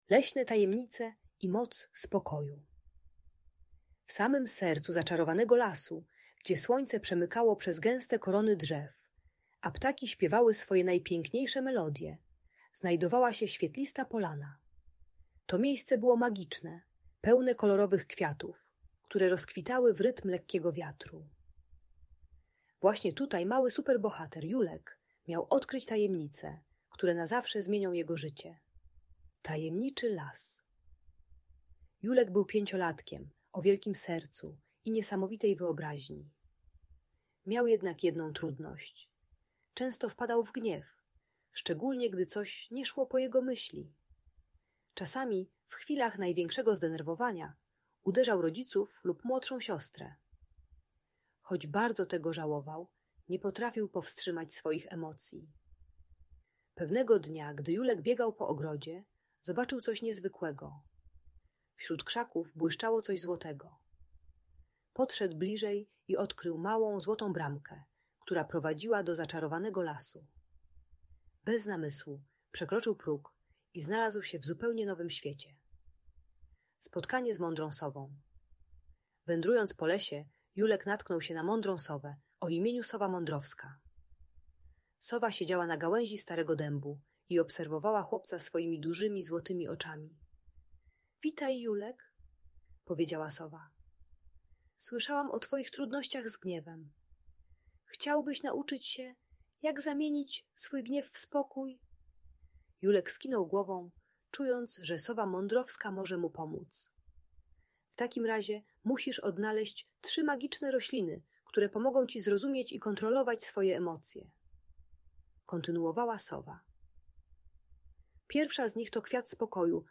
Leśne Tajemnice - Bunt i wybuchy złości | Audiobajka